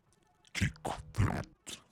• demonic techno voice "kick that 1".wav
Changing the pitch and transient for a studio recorded voice (recorded with Steinberg ST66), to sound demonic/robotic.